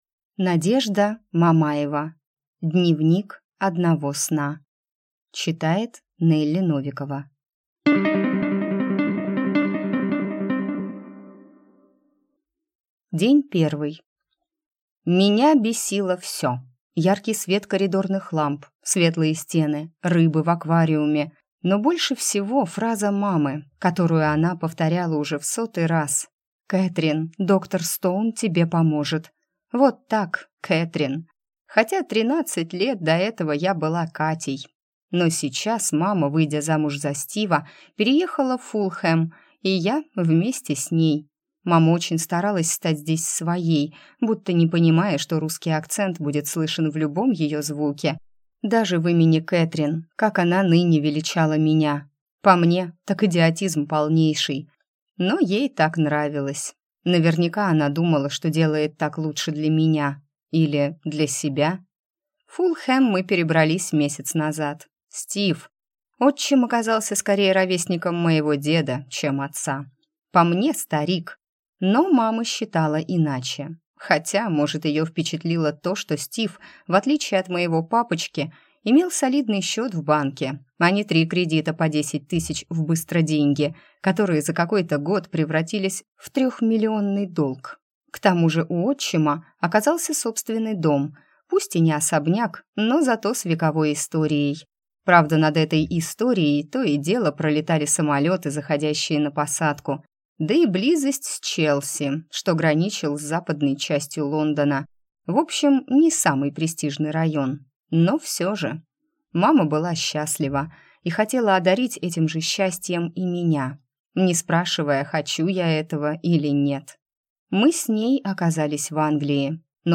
Аудиокнига Дневник одного сна | Библиотека аудиокниг